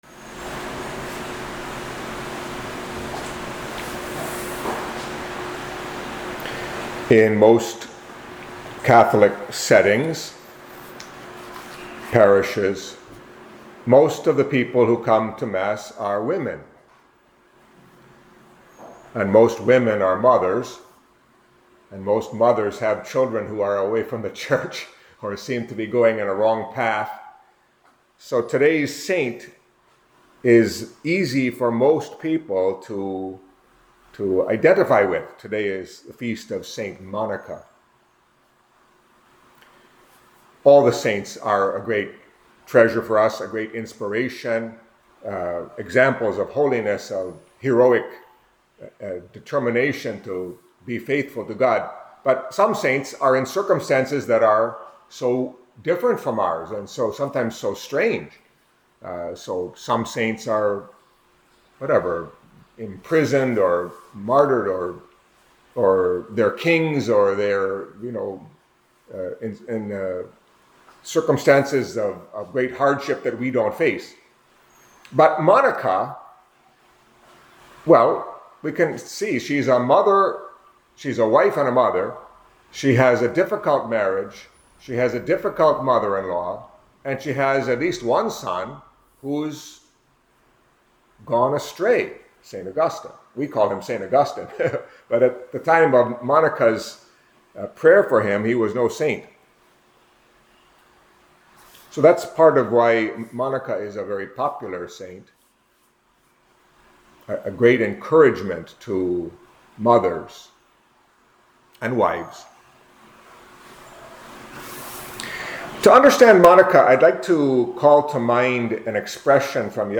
Catholic Mass homily for the Feast of St. Monica